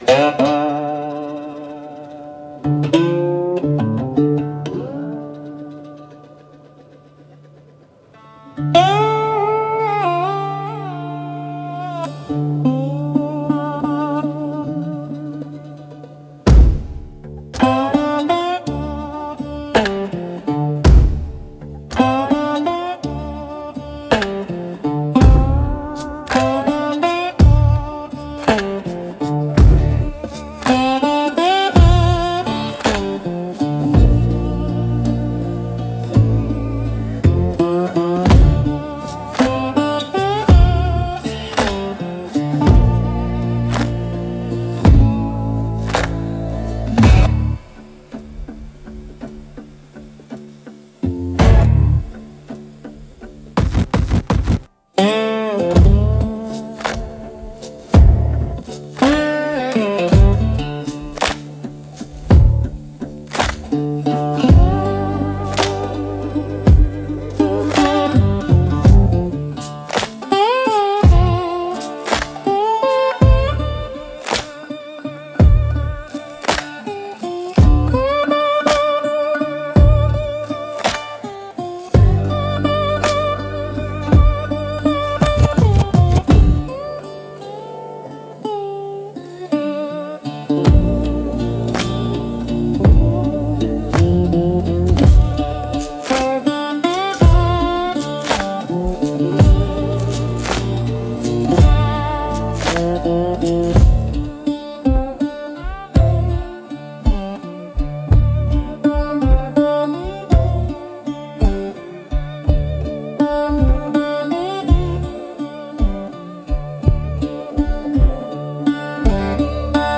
Instrumental - Blood on the Fretboard 2.04